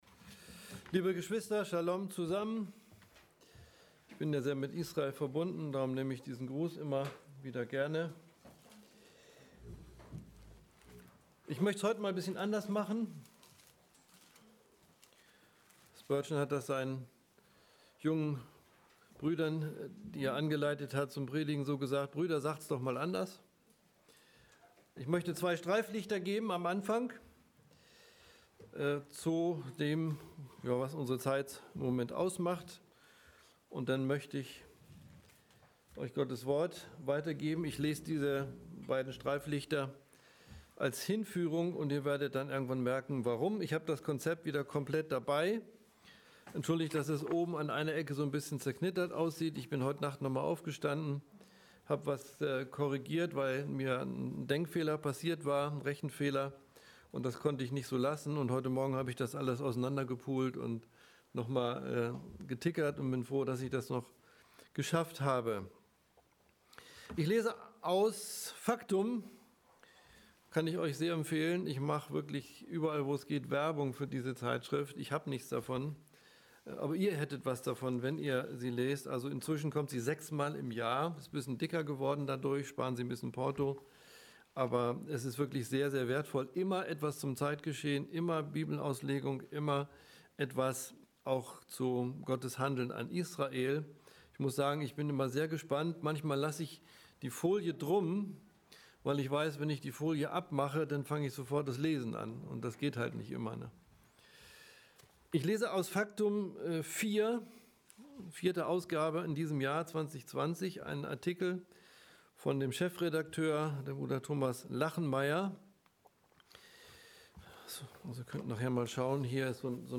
Predigt_26.07.2020